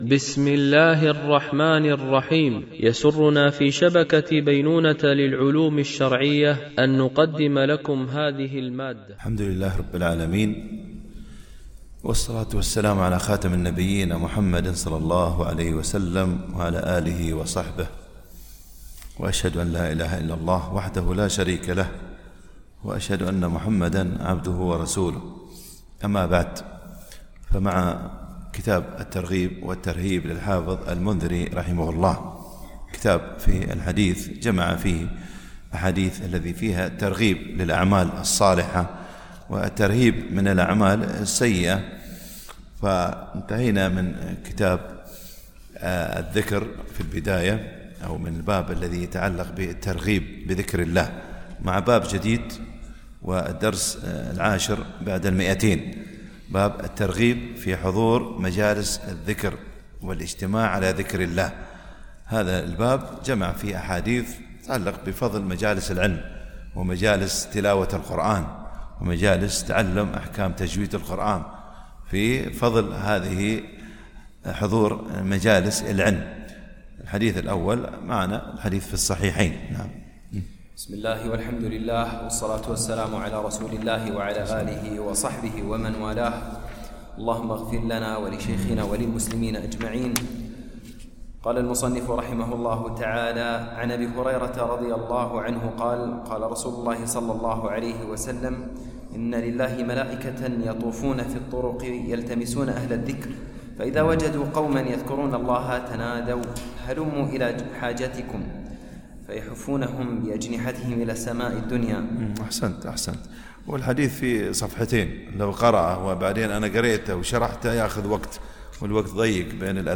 شرح كتاب الترغيب والترهيب - الدرس 210 ( كتاب الذكر والدعاء )